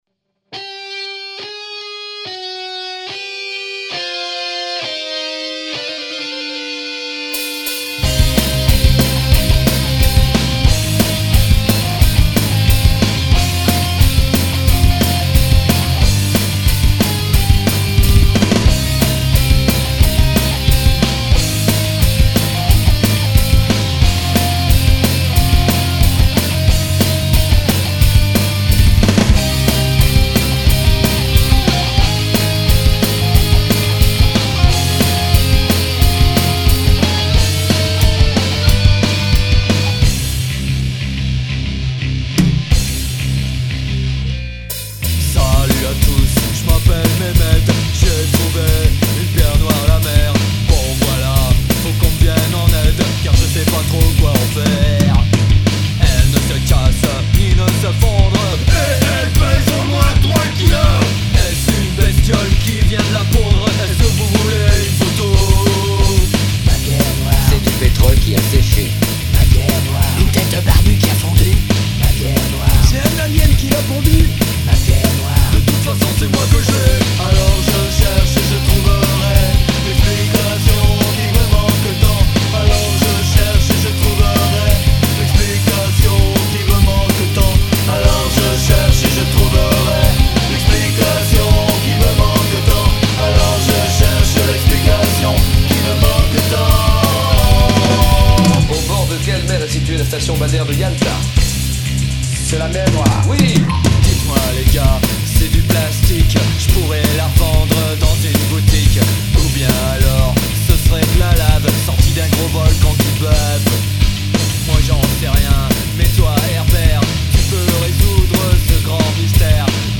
Version test d'un pote, a la batterie electronique:
y'a de sacré couilles de tempo et de placements :o
Puis il est guitariste a la base, hein :o
Ah bah c'est pour ça qu'il accélère alors [:cupra]
oui c'est un peu "sloppy" et puis le son est toujours aussi artificiel  :D